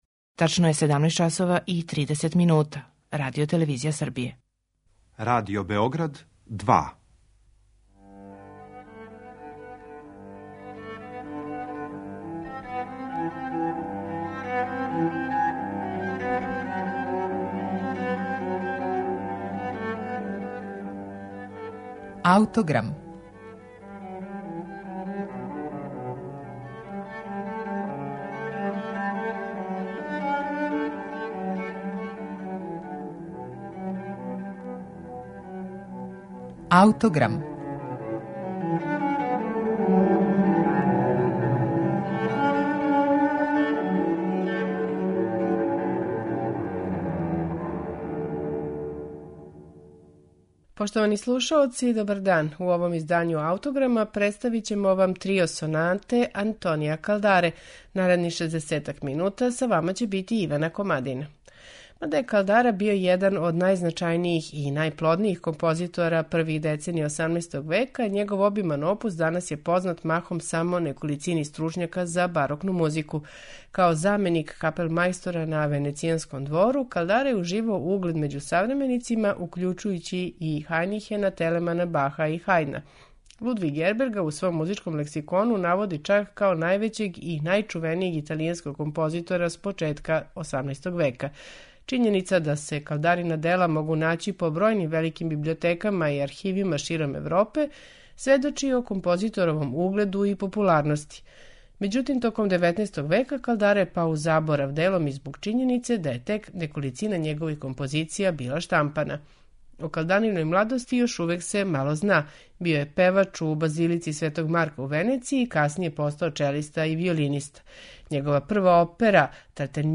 Трио сонате опус 1, Антонија Калдаре
У вечерашњем Аутограму, Трио сонате опус 1, Антонија Калдаре, слушаћете у интерпретацији чланова ансамбла Parnassi Musici